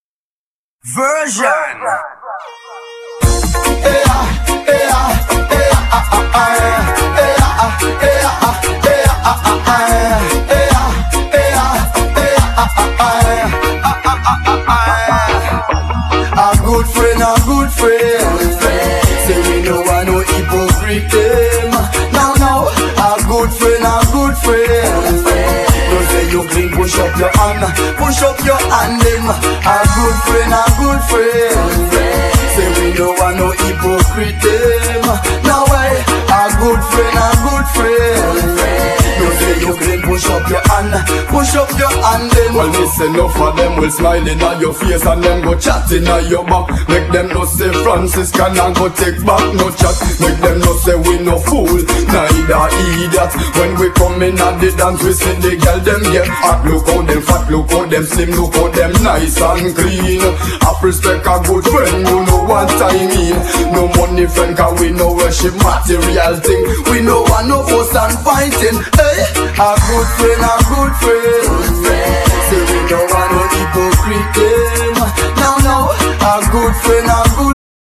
Genere : Raggea